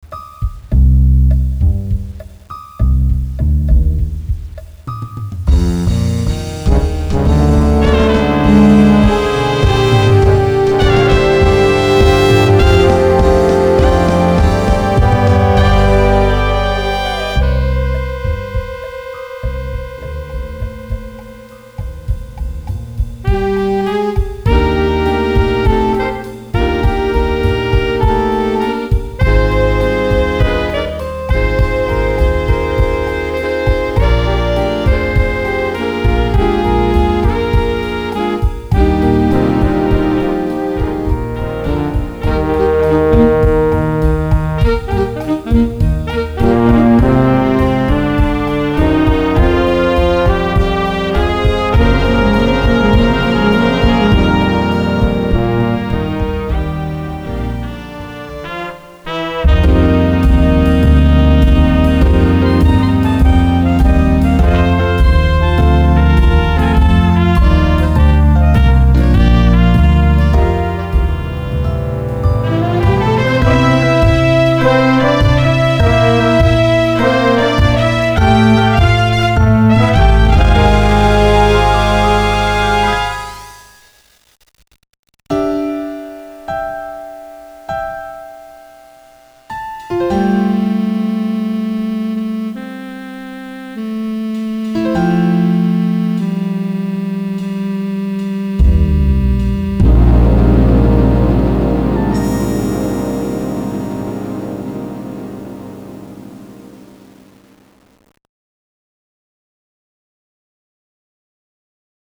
Style: Classical, Bossa, Dixieland, Broadway, Ballad.
Instrumentation: Standard Jazz Band